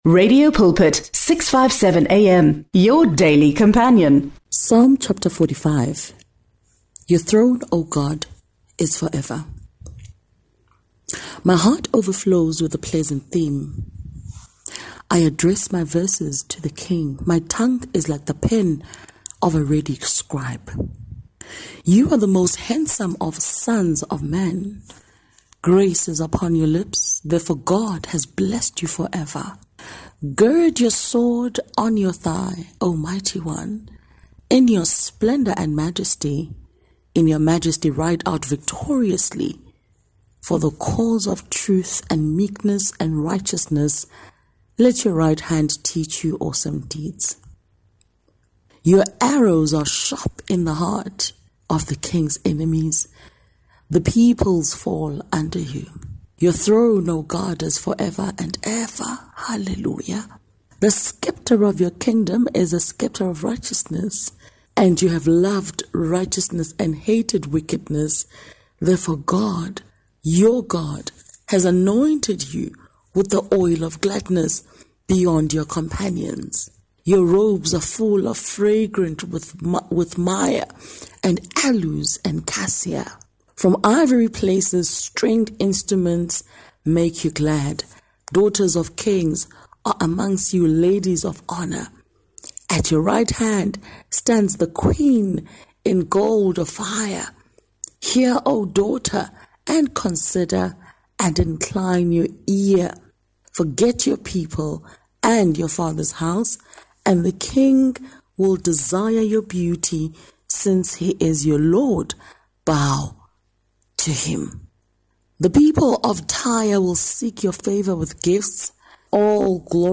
Prayer of Intercession for self, communities and the nation.